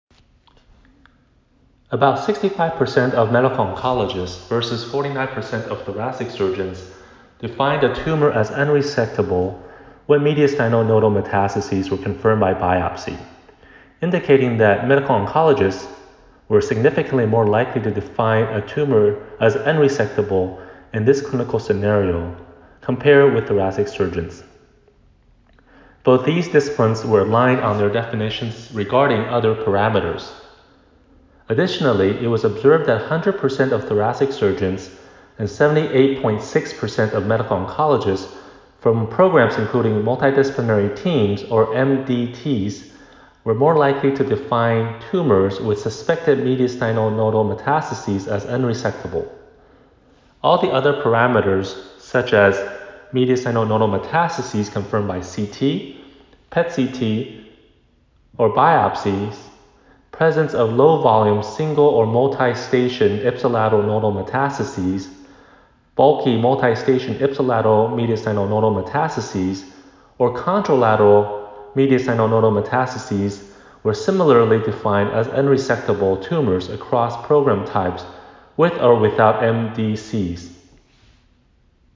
Presented at the ASTRO 2020 Annual Meeting.